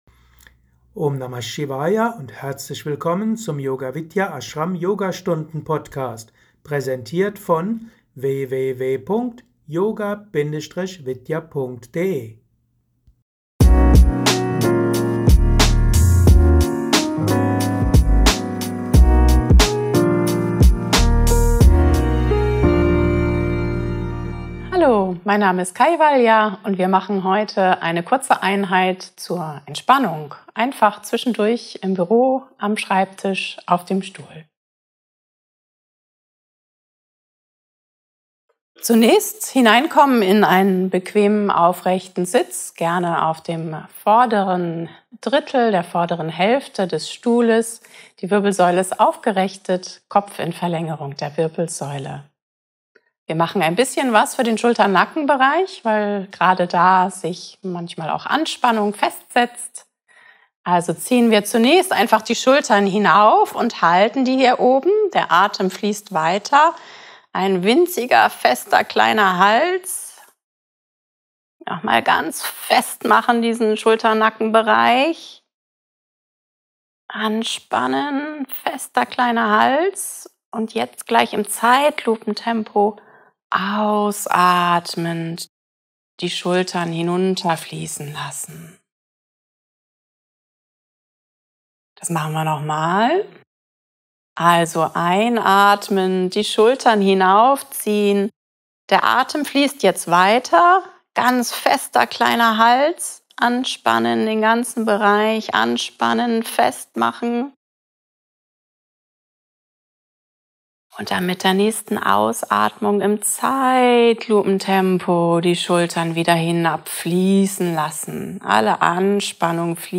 Mit Anleitung zur tiefen Bauchatmung für noch mehr Harmonie und Entspannung.